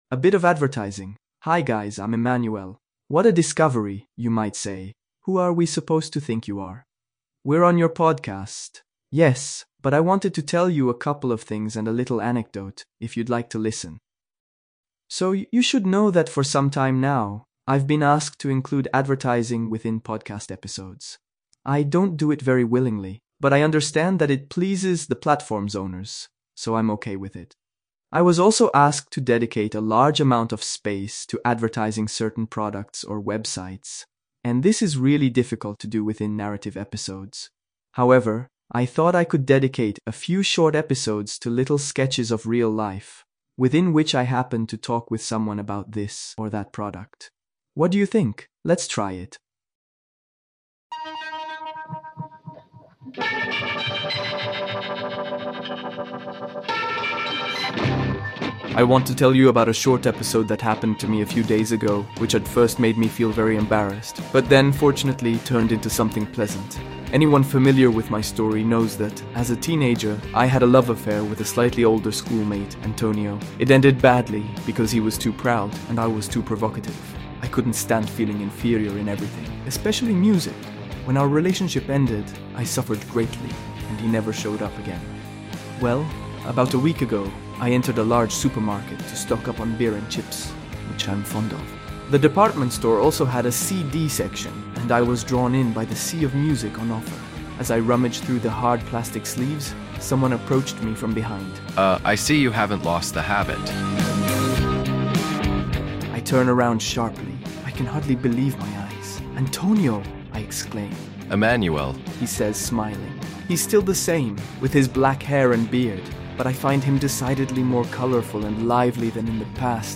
The broken diary (Podcast Novel)That time I saw Antonio again
The soundtrack consists of an instrumental version of "Magdalena 318" by the Pixies.